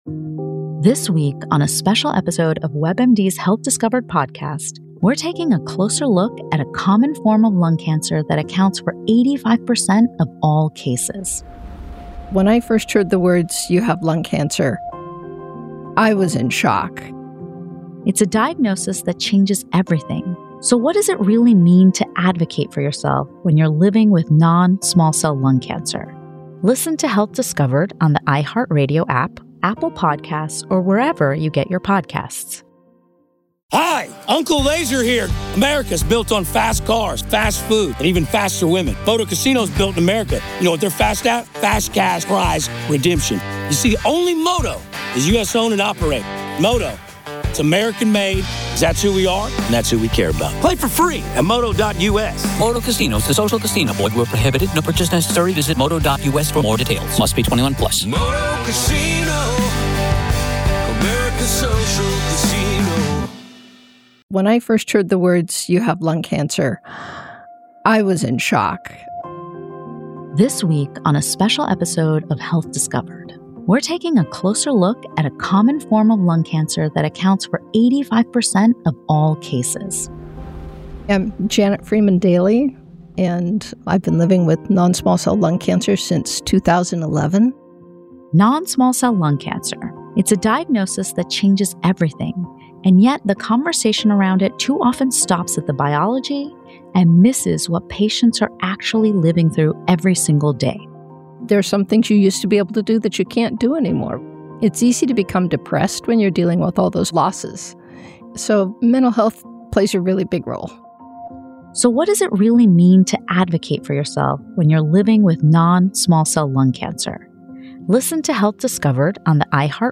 True Crime Today | Daily True Crime News & Interviews / LISK Case: The Prosecution That Cornered Rex Heuermann